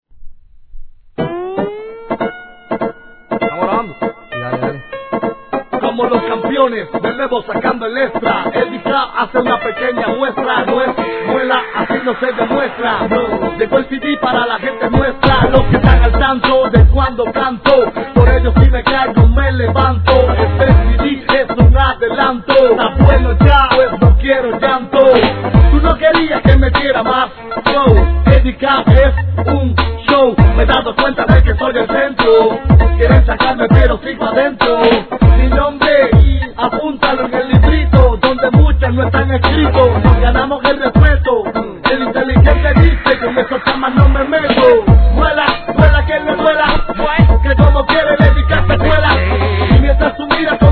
■REGGAETON